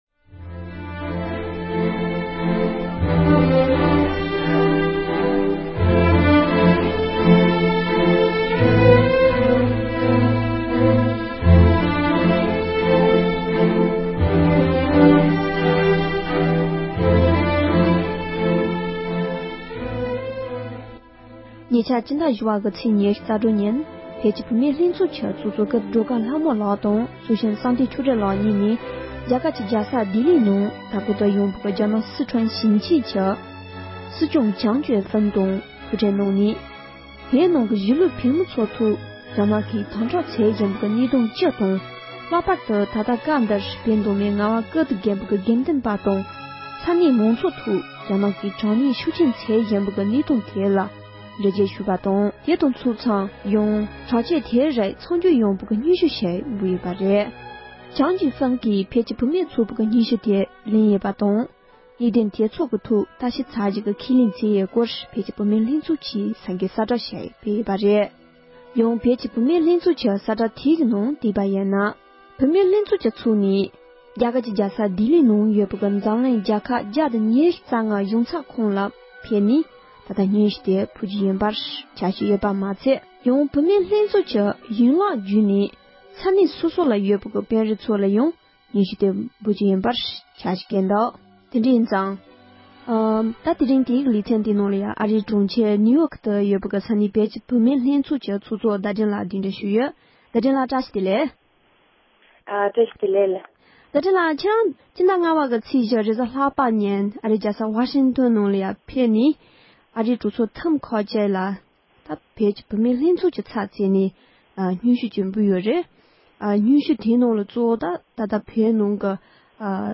འབྲེལ་ཡོད་མི་སྣར་བཅར་འདྲི་ཞུས་པ་ཞིག